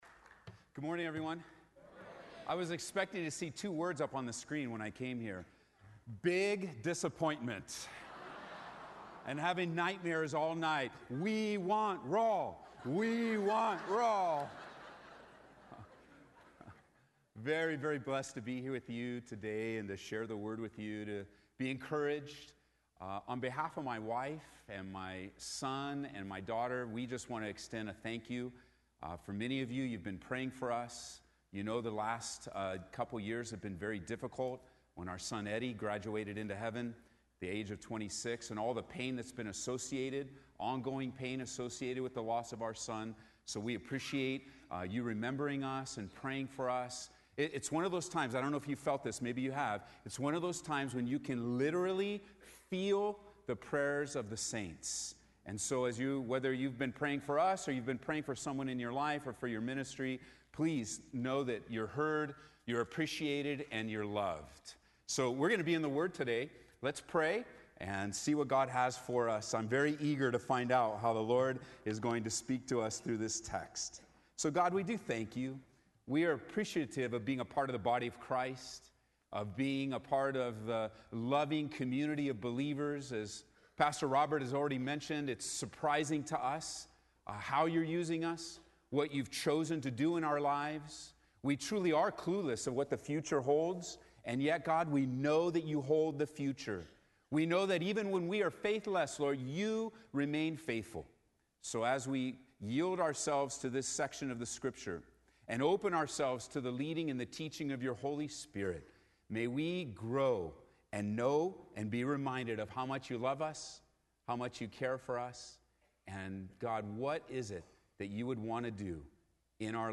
at the 2015 SW Pastors and Leaders Conference